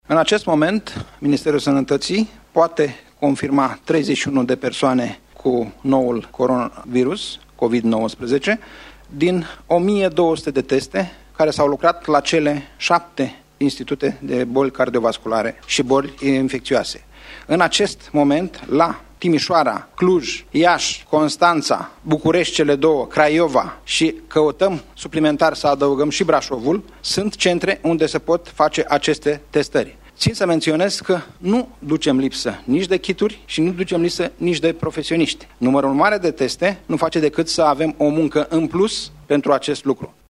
Cu această ocazie, secretarul de stat în Ministerul Sănătății, Nelu Tătar a declarat că nu sunt lipsuri în ce privește numărul de teste disponibile și personalul necesar pentru efectuarea acestor teste: